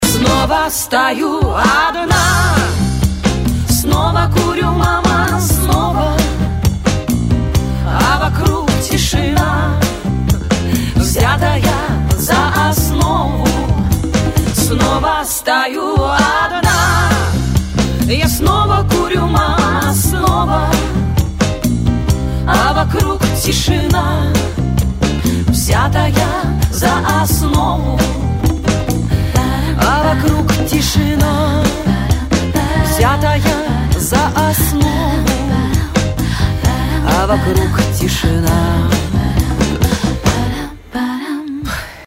Танцевальные рингтоны , Рингтоны шансон
Грустные